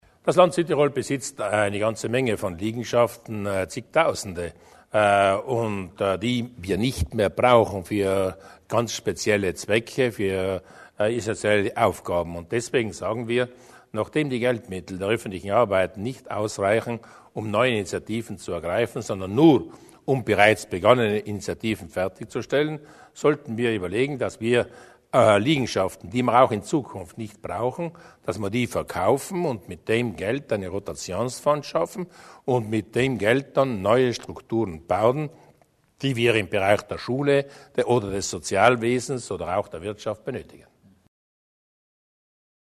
Landeshauptmann Durnwalder über die Verwendung öffentlicher Immobilien